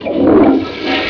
A sound that we hear all the time.
toilet01.wav